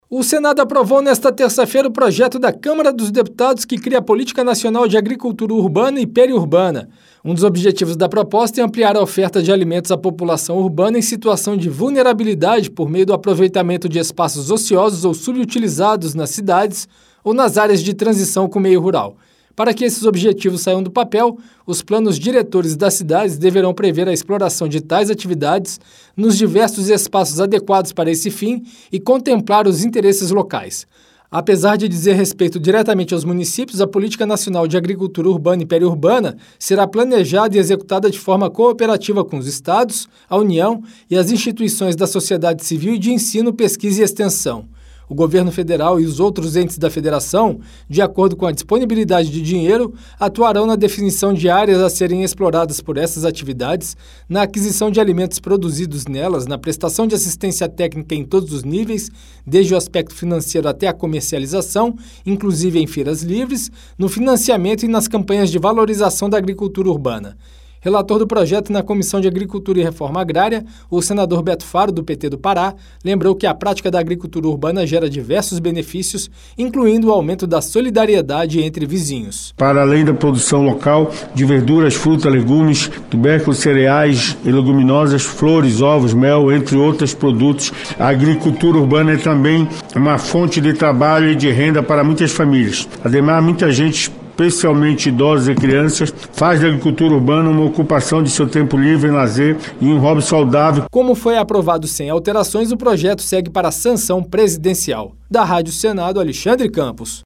Plenário
O objetivo é estimular a produção de alimentos nas cidades e nas áreas de transição com o meio rural, pelo aproveitamento de espaços ociosos. Relator da matéria na Comissão de Agricultura e Reforma Agrária, o senador Beto Faro (PT-PA) afirmou que a política vai gerar renda para as famílias.